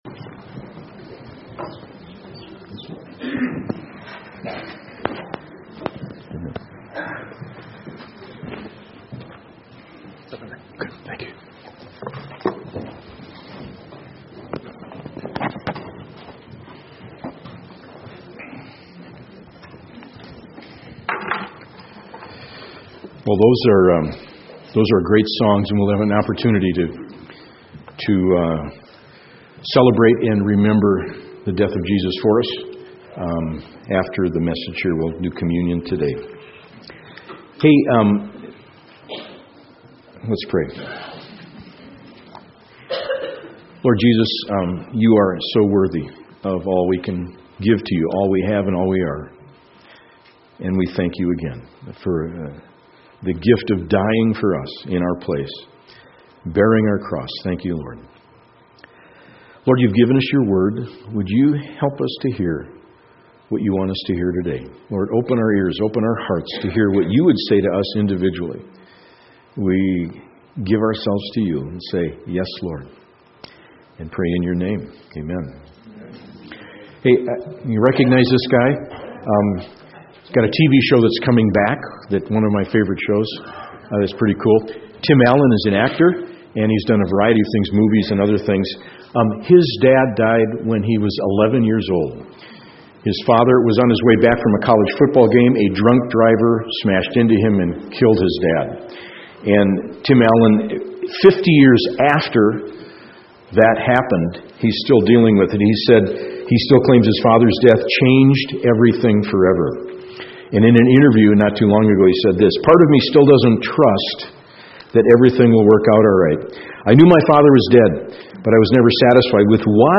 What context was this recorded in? – Chattaroy Community Church